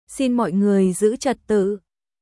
Xin mọi người giữ trật tự.シン モイ グオイ ズ チャット トゥ皆さん、静粛にお願いします